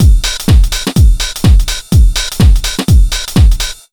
125BEAT2 4-R.wav